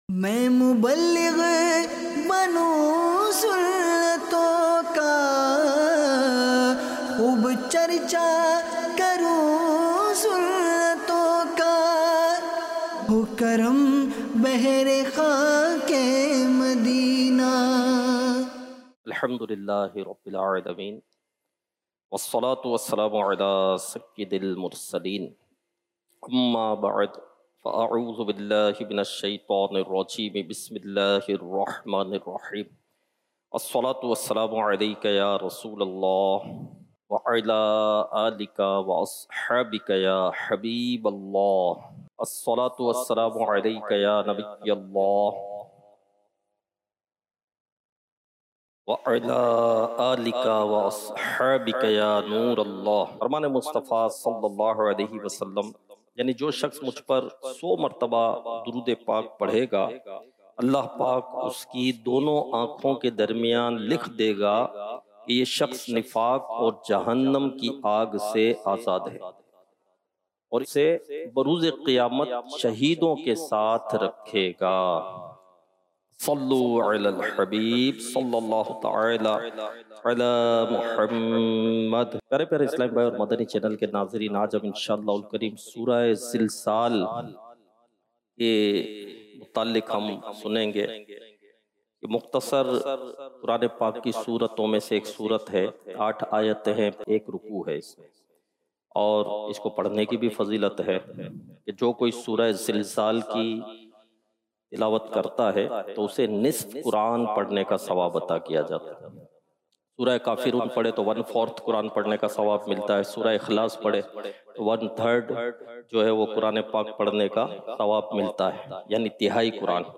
سنتوں بھرا بیان –